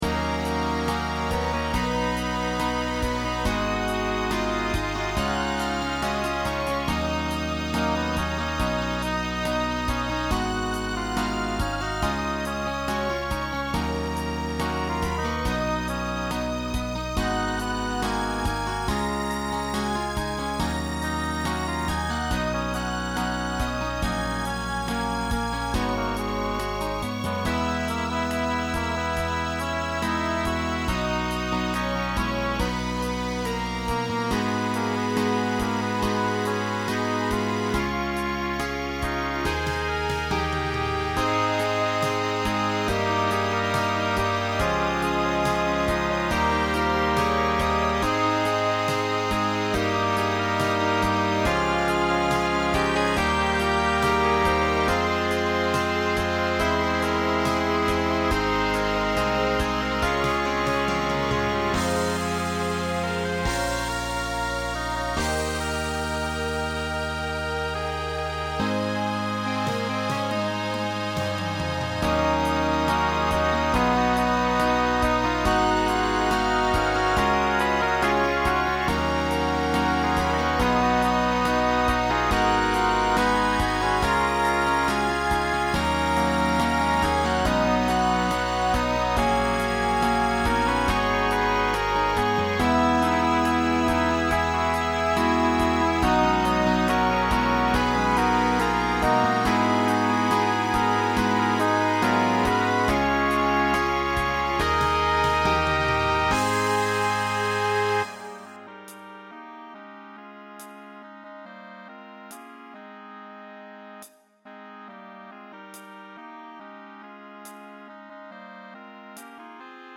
Voicing SATB Instrumental combo Genre Pop/Dance
Ballad